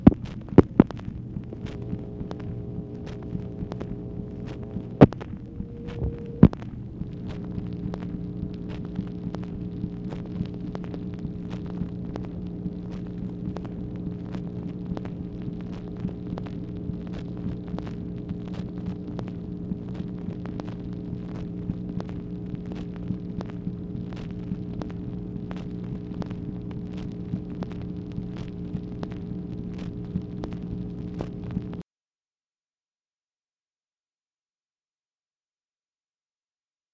MACHINE NOISE, October 22, 1965